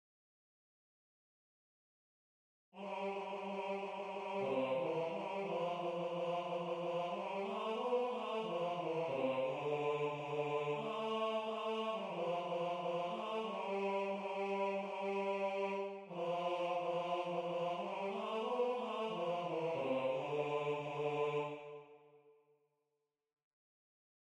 MP3 rendu voix synth.
Basse 1